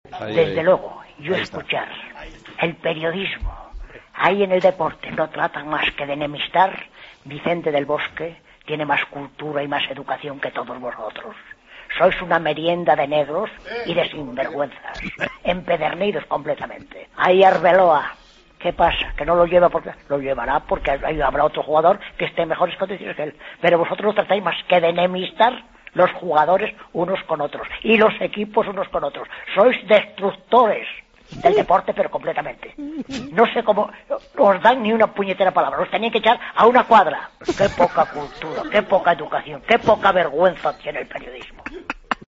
El oyente enfurecido